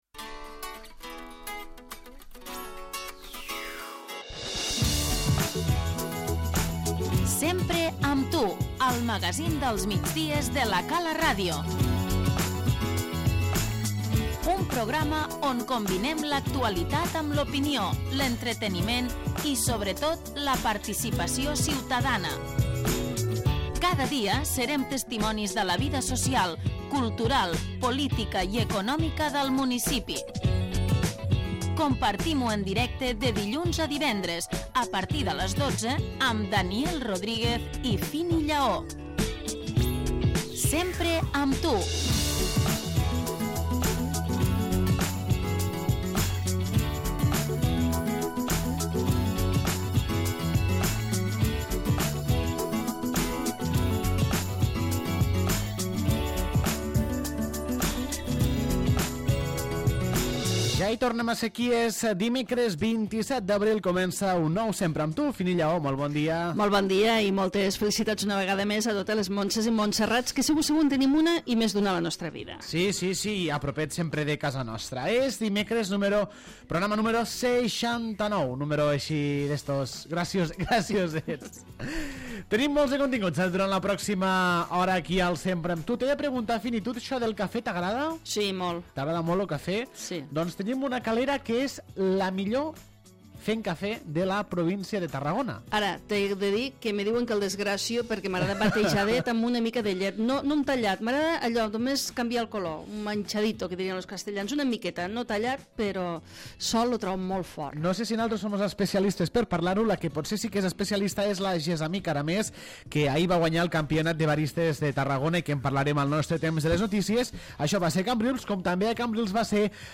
Avui dimecres dia 27 d'abril de 2016, al magazín dels migdies, al Sempre amb tu, hem tractat els següents temes: